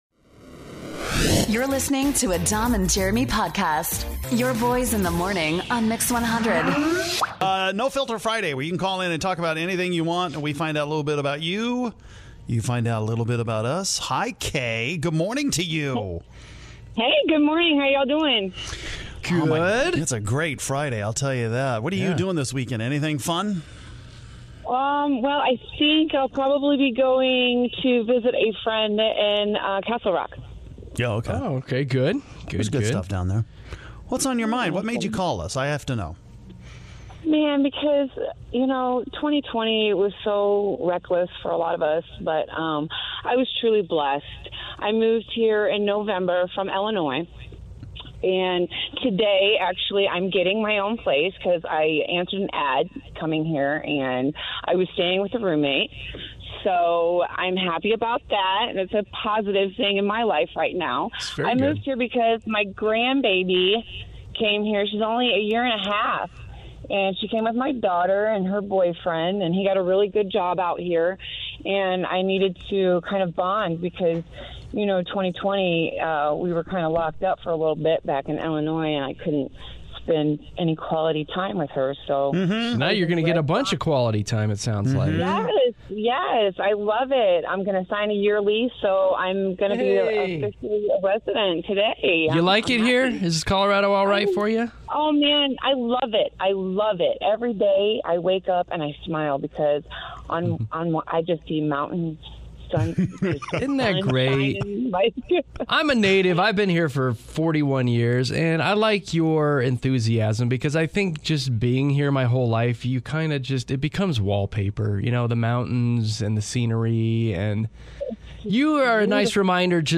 Some GREAT calls from listeners today